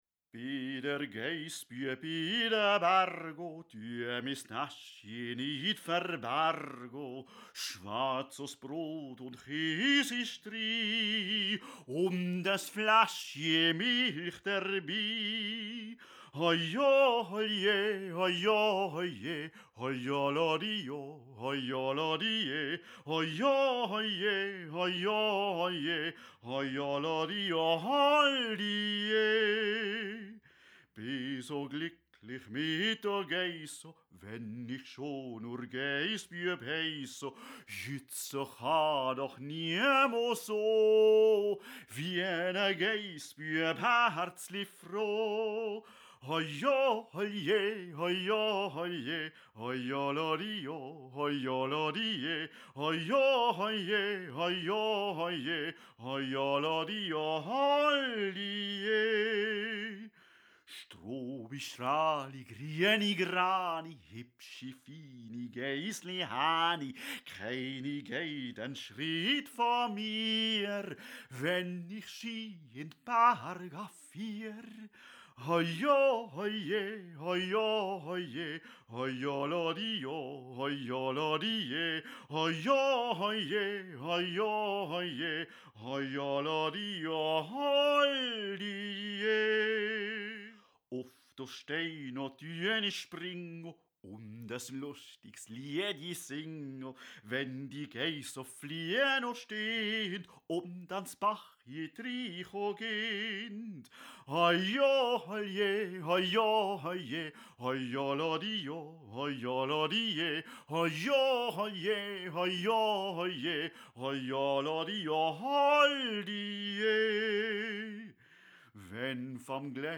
__ für den Hausgebrauch eingerichtet
Hauptstimme solo
Haupt- und Nebenstimme gemeinsam